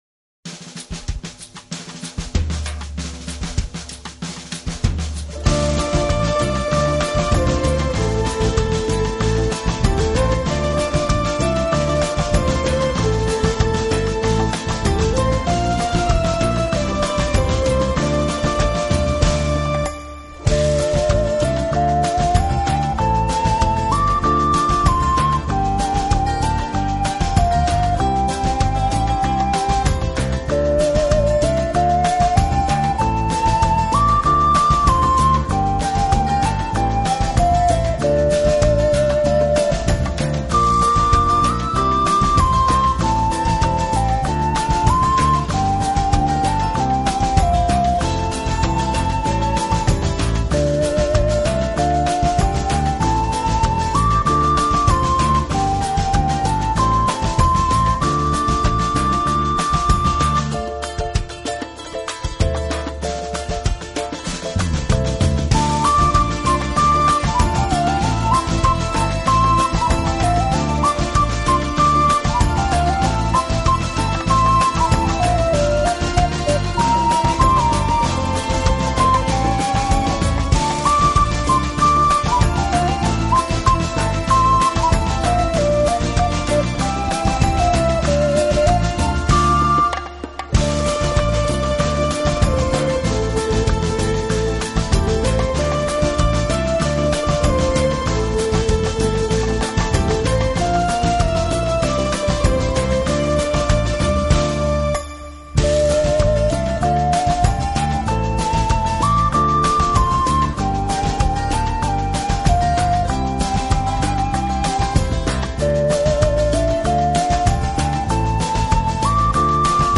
【陶笛】
音乐类型：Newage-Ethnic